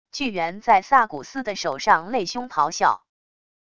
巨猿在萨古斯的手上擂胸咆哮wav音频